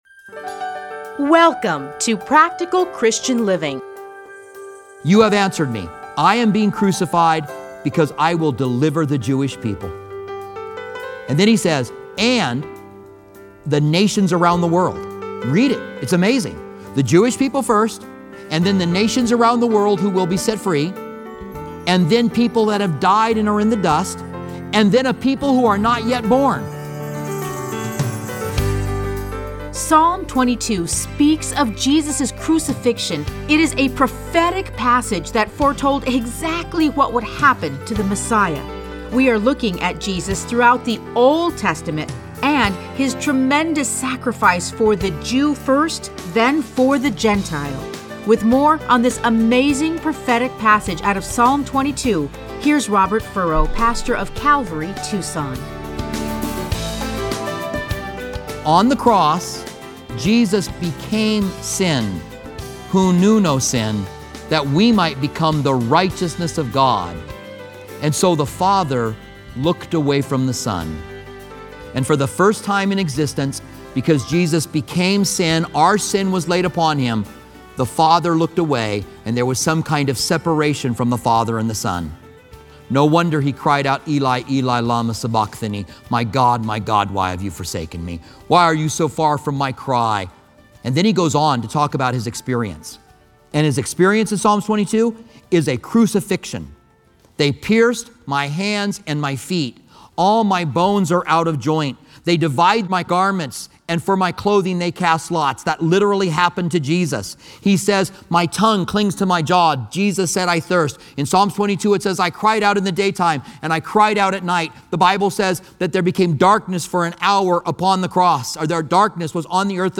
Listen to a teaching from Luke 18:31-34.